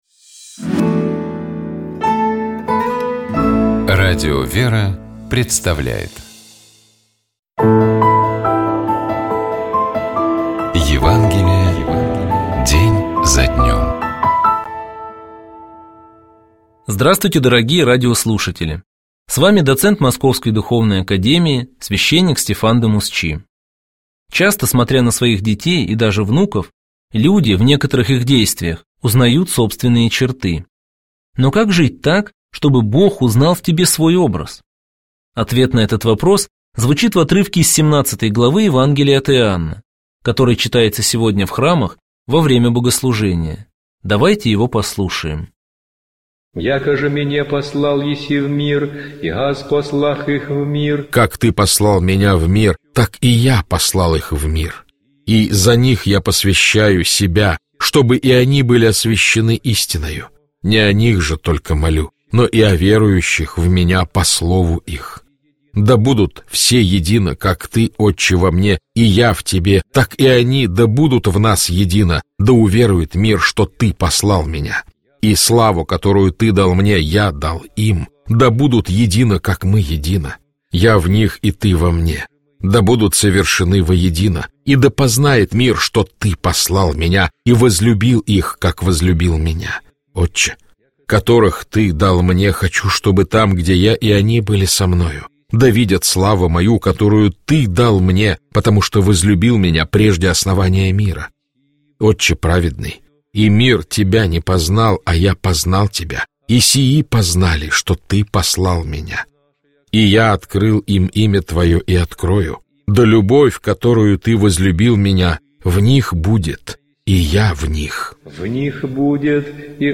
Библейские чтения
Читает и комментирует протоиерей